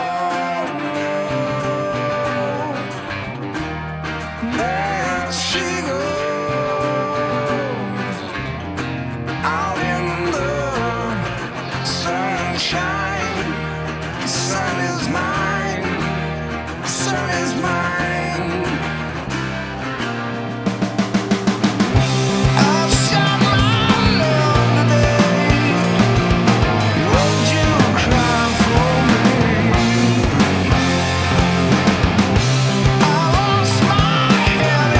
Жанр: Рок / Альтернатива / Метал